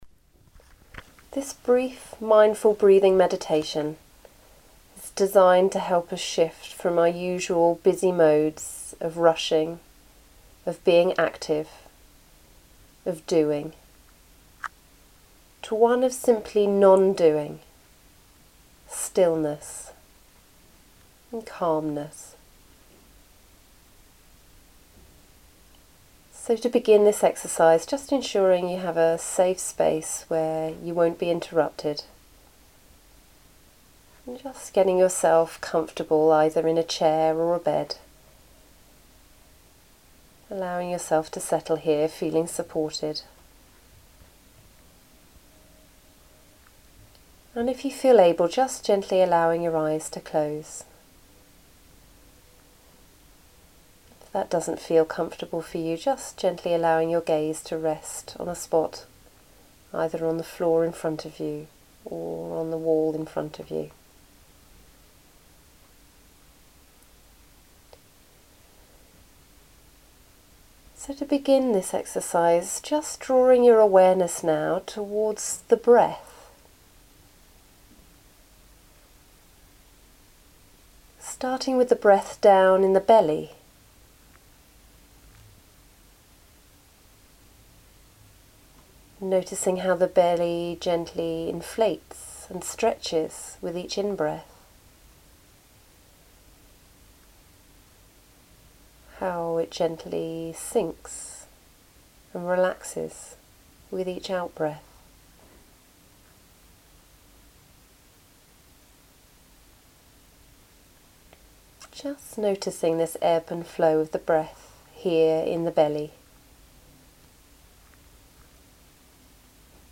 Mindfulness breathing.MP3